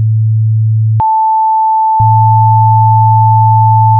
tploctave.wav